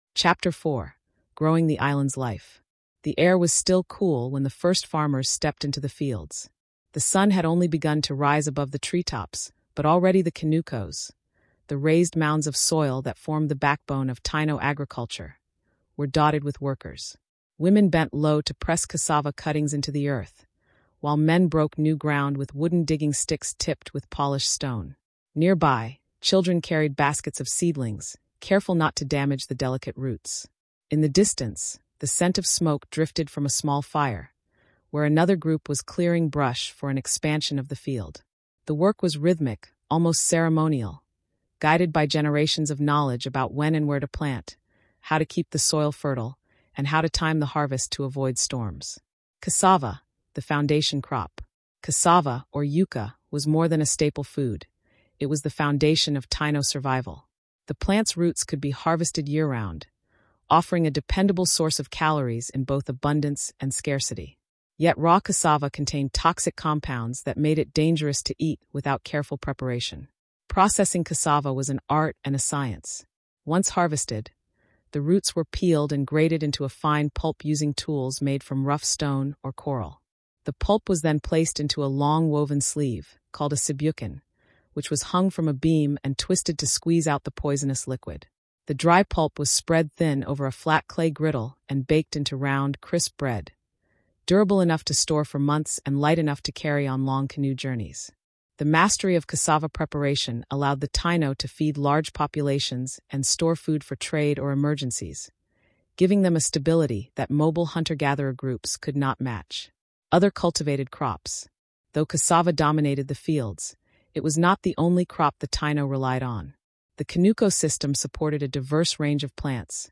Told through immersive first-person storytelling, expert interviews, and archaeological insight, this is the untold story of the Taíno on Hispaniola, Cuba, Puerto Rico, and Jamaica.